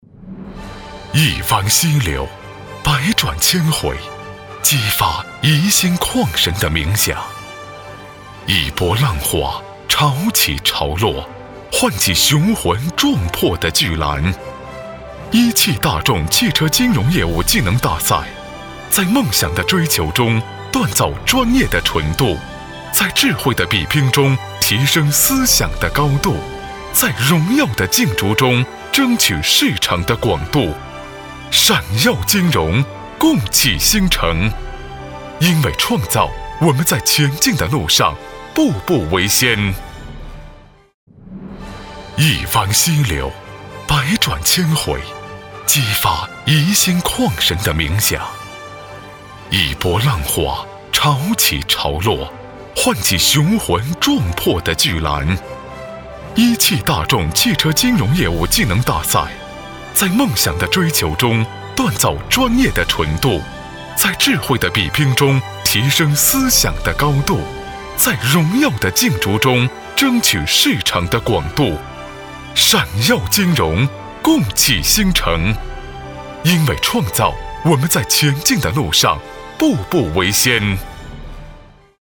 国语青年大气浑厚磁性 、沉稳 、科技感 、男专题片 、宣传片 、200元/分钟男S355 国语 男声 宣传片-大众-企业宣传-大气浑厚 大气浑厚磁性|沉稳|科技感